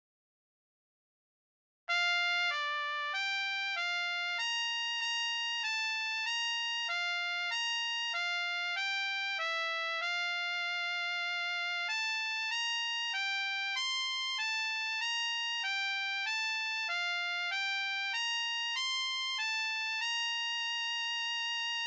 A=Melody-for more experienced players